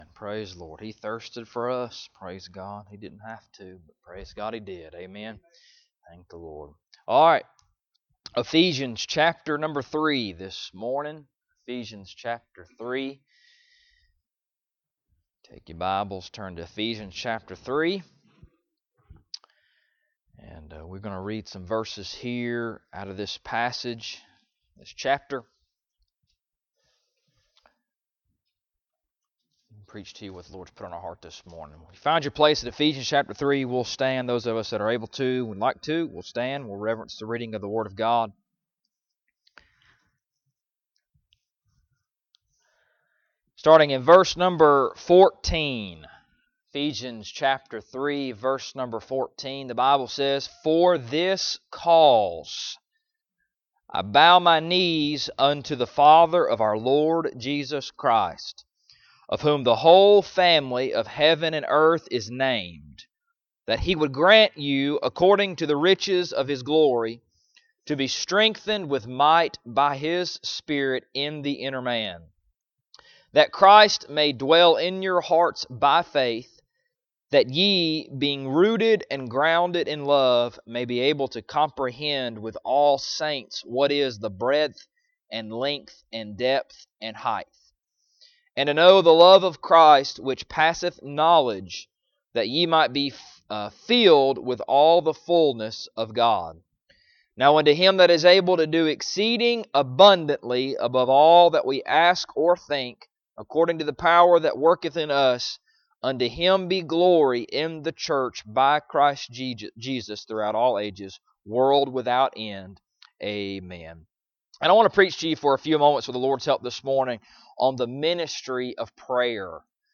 Ephesians Passage: Ephesians 3:14-21 Service Type: Sunday Morning Topics